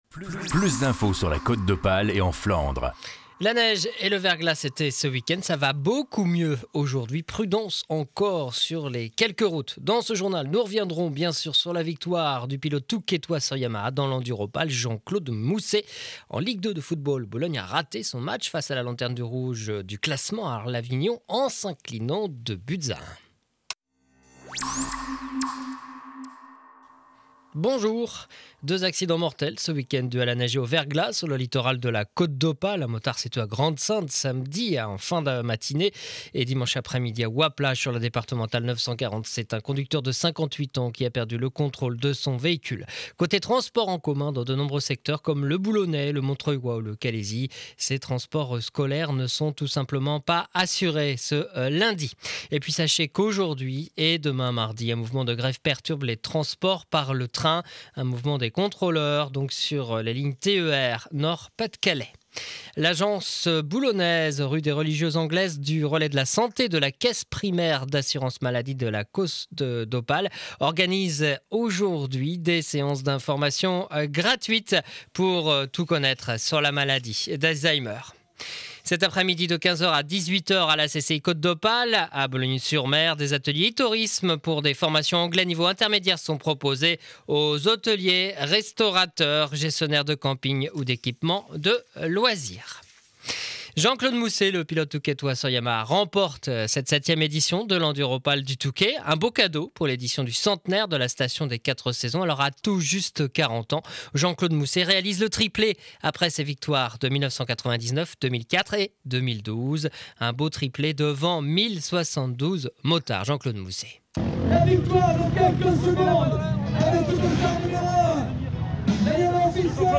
Edition de 12h journal de Boulogne sur mer